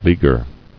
[lea·guer]